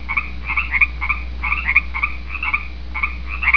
The pacific tree frogs are small guys but they make a lot of noise.
The Pacific Tree Frog has a very loud ribit.
The Song of the Pacific Tree Frog
at their home in the Santa Cruz Mountains.
frogs.wav